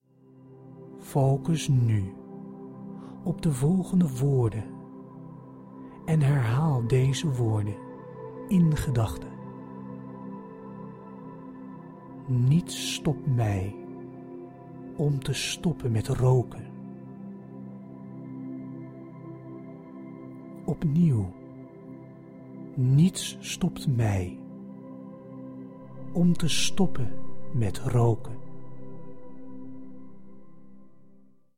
Stop nu voorgoed middels deze zelfhypnose sessie en stop met jezelf overtuigen waarom het ‘okay’ is om door te blijven roken.